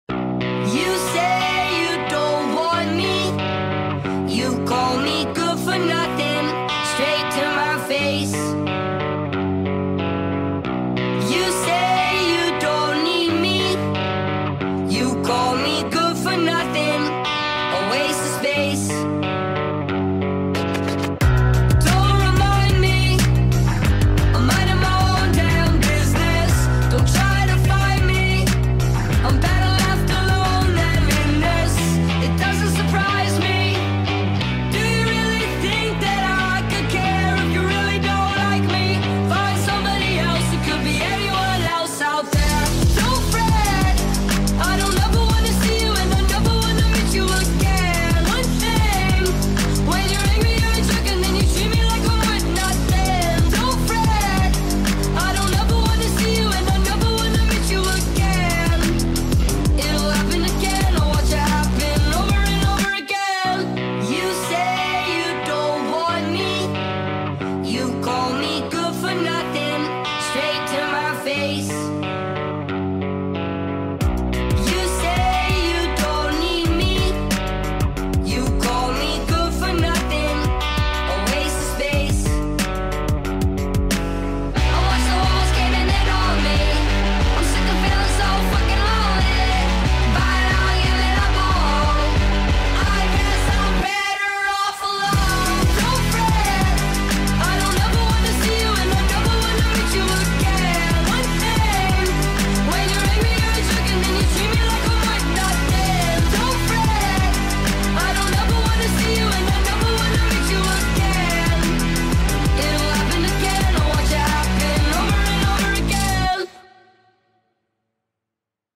speed up remix